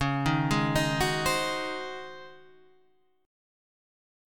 DbM7sus2sus4 chord